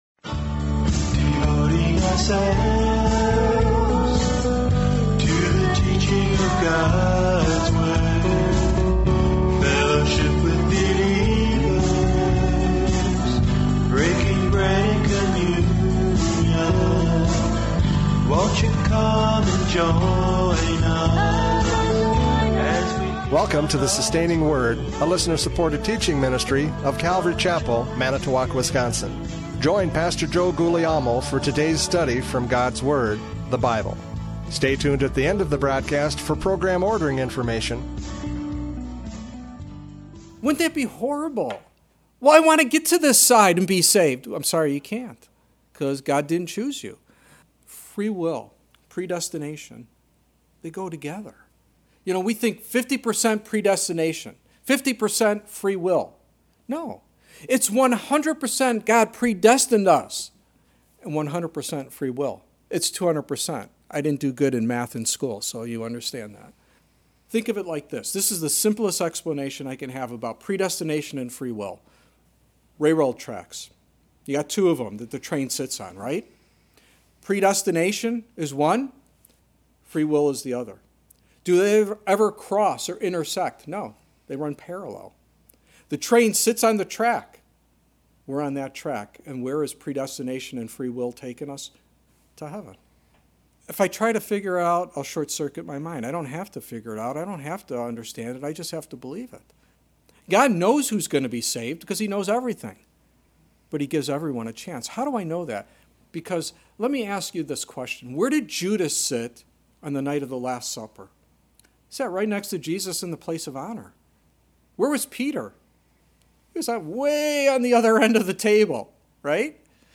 John 3:13-21 Service Type: Radio Programs « John 3:13-21 God’s Love For Us!